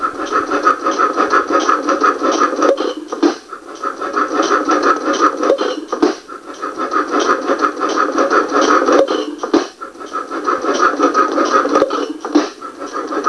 The engine is being run with a buzz coil and spark plug right now but will have a hot tube ignition soon...
To hear a 13 second wav file of the 4 HP Buffalo Olin running...